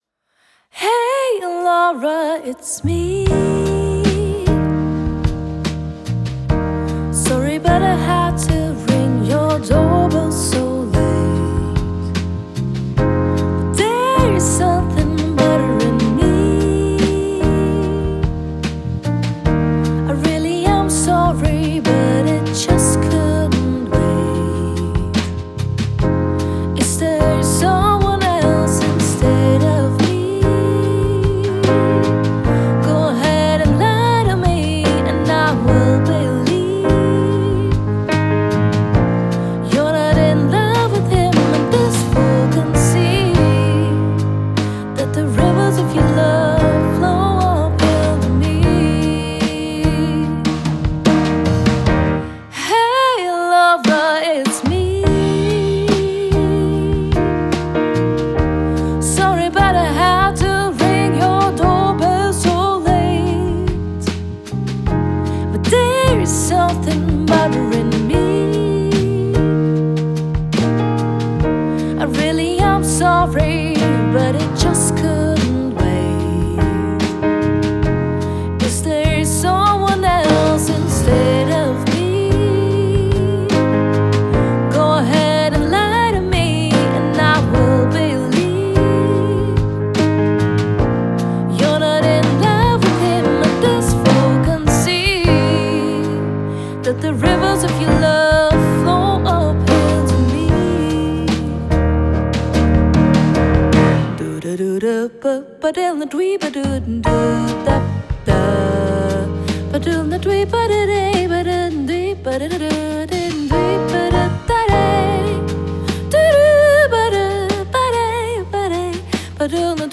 Gesang, Bandleaderin
Klavier
E-Bass, Kontrabass
Schlagzeug